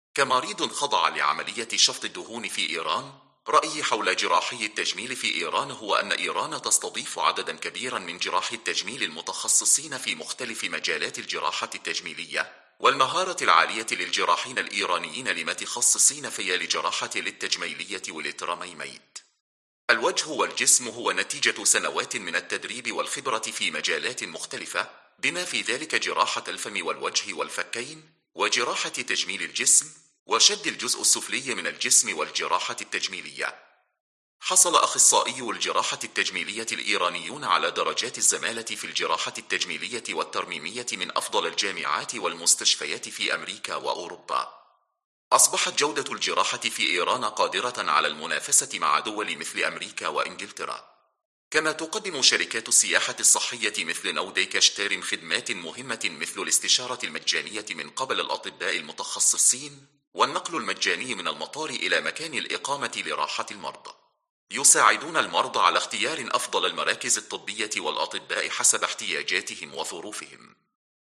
تجربة المرضى مع جراحي التجميل في إيران